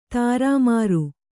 ♪ tārā māru